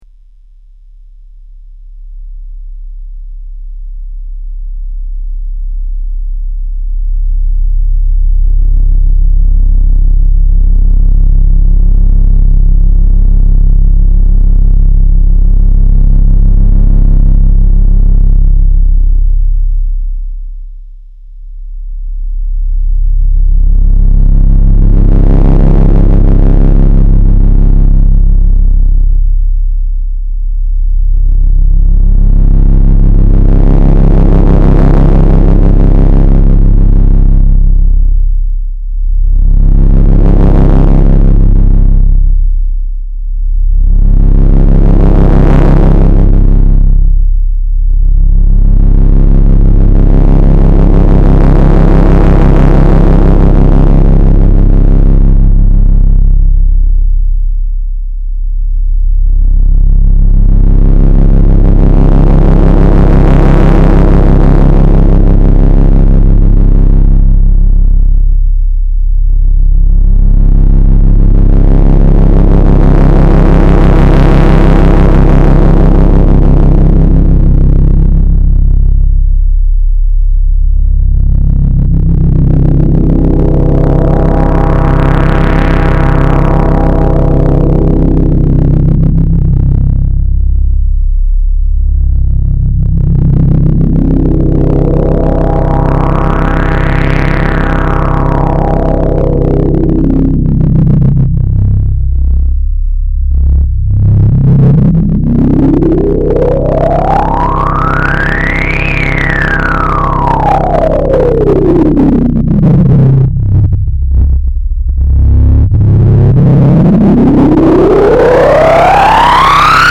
Analog monophonic Synthesizer
filter FILTER resonant analog filter lowpass mode at -24dB good sounding with a medium lack of bass bottom at high resonance (2).
- grungy OSC/Filter